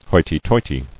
[hoi·ty-toi·ty]